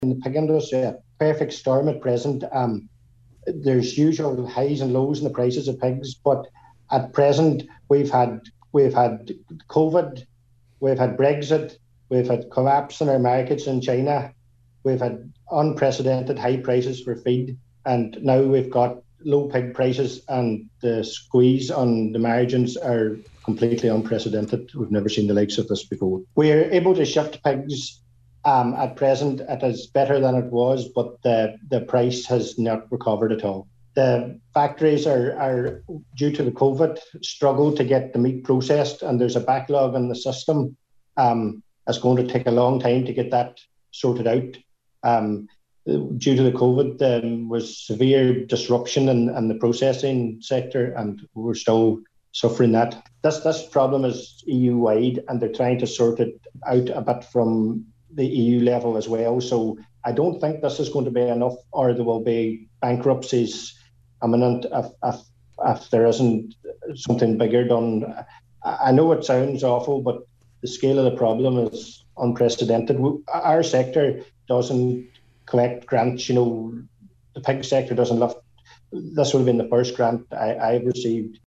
A local pig farmer has described the situation facing the sector at present as the perfect storm.